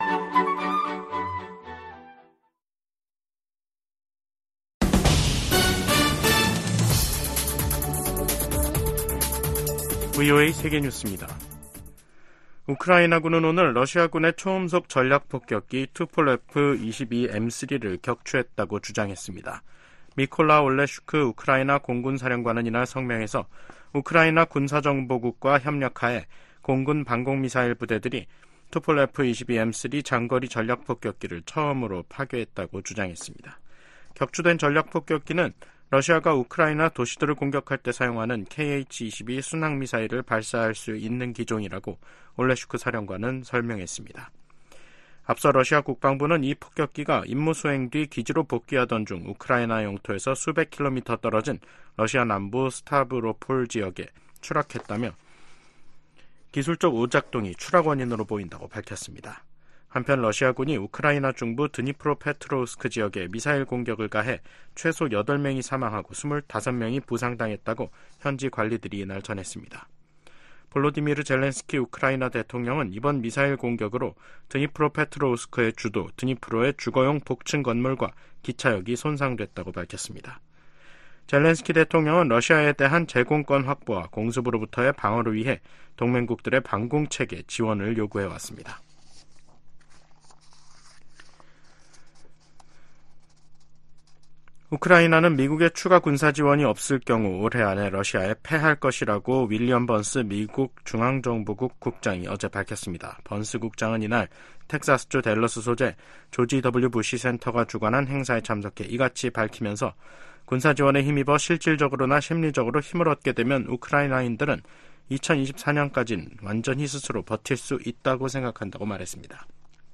VOA 한국어 간판 뉴스 프로그램 '뉴스 투데이', 2024년 4월 19일 2부 방송입니다. 일본을 방문 중인 미국 유엔대사가 유엔 총회나 외부 기관 활용 등 대북제재 패널 활동의 대안을 모색하고 있다고 밝혔습니다. 미국이 한국과 우주연합연습을 실시하는 방안을 추진 중이라고 미국 국방부가 밝혔습니다.